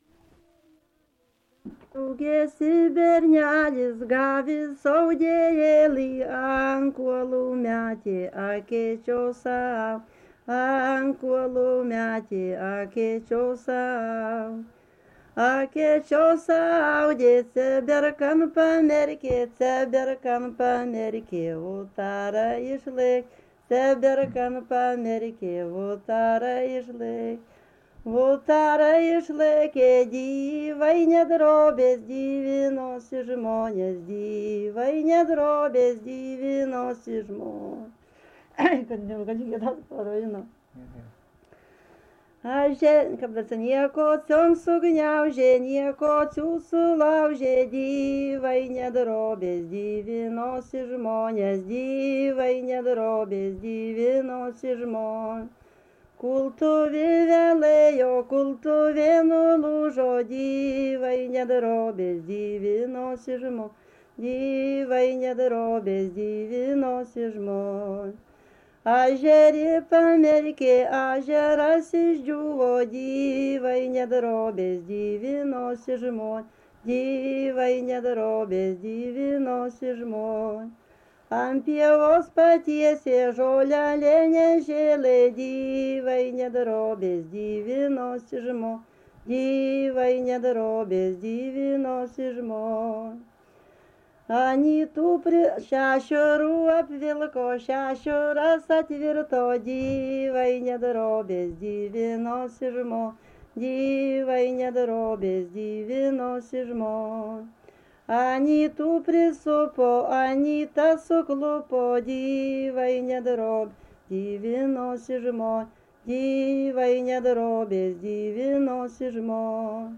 Subject daina
Erdvinė aprėptis Druskininkai
Atlikimo pubūdis vokalinis